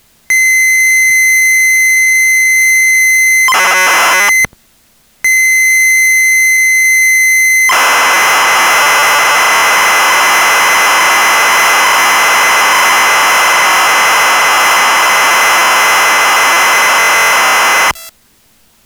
Having a listen to them, they don't appear to be the best quality recording, I'm wondering if you could have a go at re-recording them at a lower volume/with less interference?
They weren't working for me at a lower capture volume, either, so I tried capturing them as loud as I could without any visible clipping.
Both sides play back at different volumes.